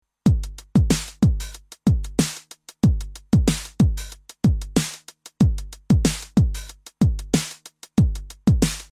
This makes the 909’s cymbals sound much more aggressive compared to the 808’s more subdued analog hi-hats.
Roland TR-909